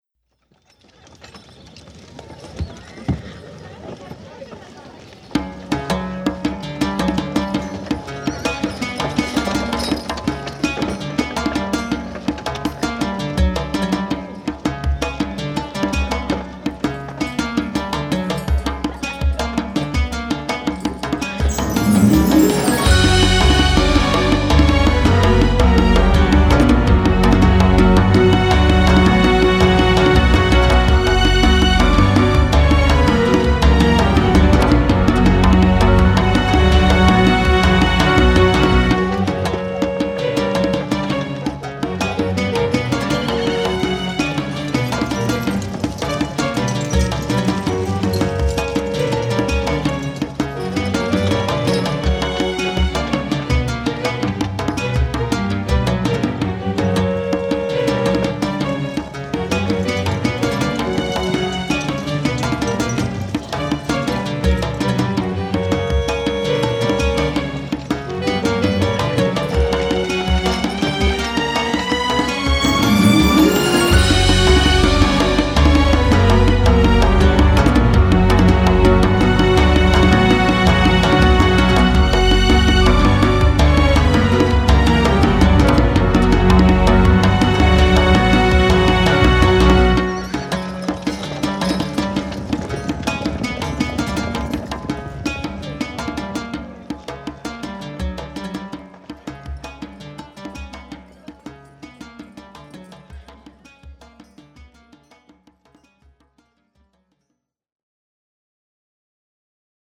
World
musique pour documentaire